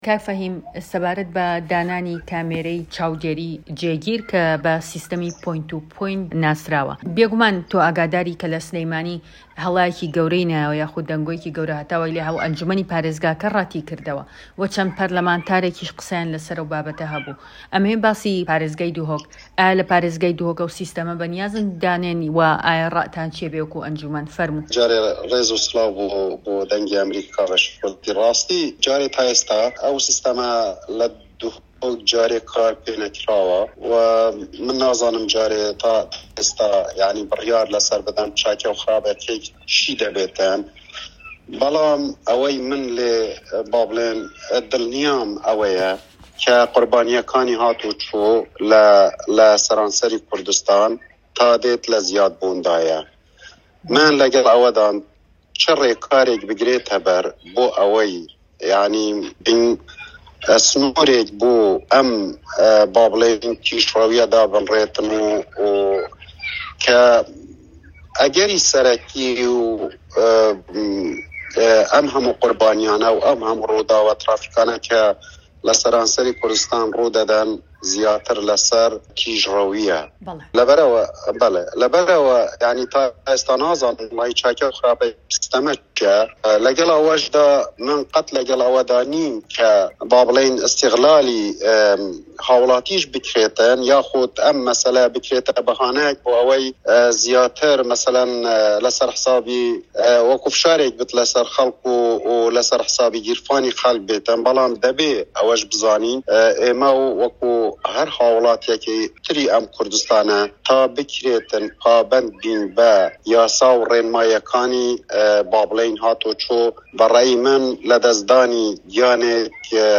فه‌هیم عه‌بدوڵا سه‌رۆكی ئه‌نجومه‌نی پارێزگای دهۆک
دەقی وتووێژەکەی فەهیم عەبدوڵا